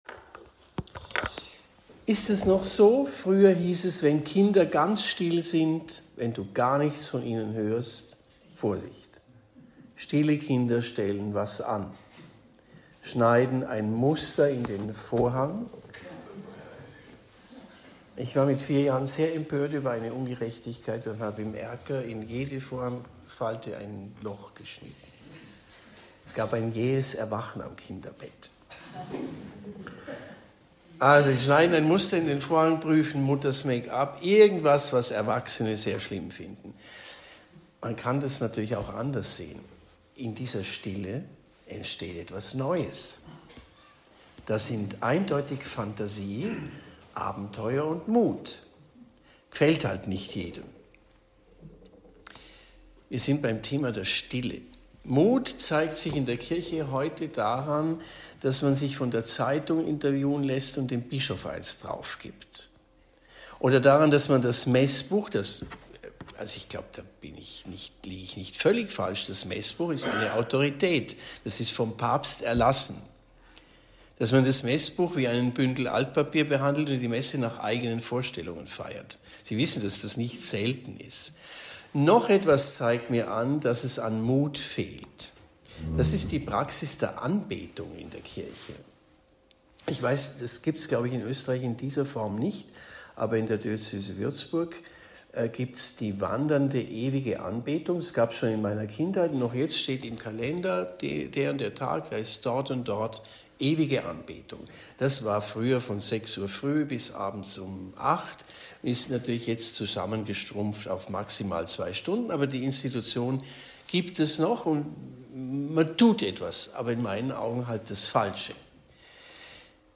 Die Stille - Vortrag bei den Ordensexerzitien in Stift Schlägl